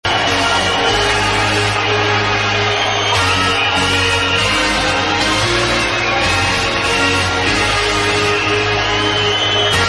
Kinda sounds liek a trance version